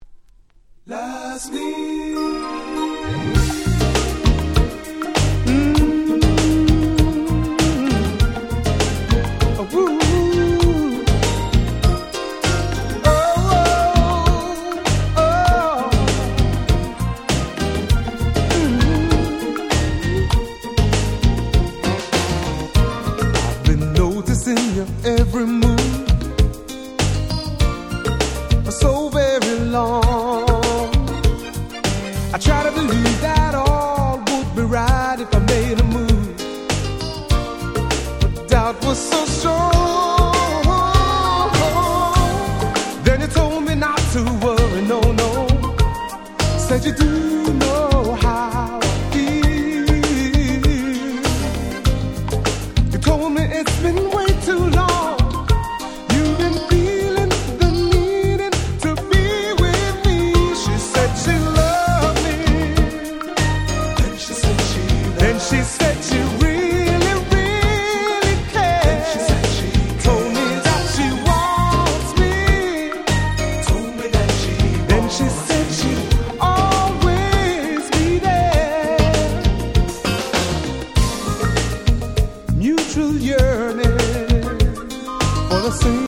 92' Nice UK R&B LP !!